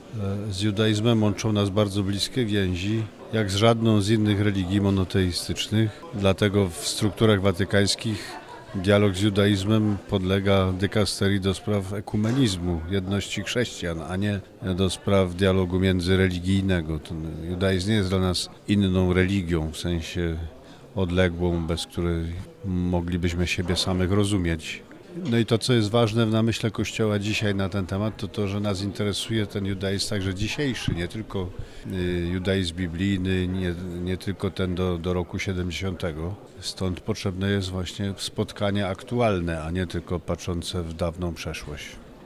O znaczeniu odbywającego się po raz 28. Dnia Judaizmu w Kościele Katolickim mówi kard. Grzegorz Ryś, który jest również przewodniczącym Rady ds. Dialogu Religijnego i Komitetu ds. Dialogu z Judaizmem.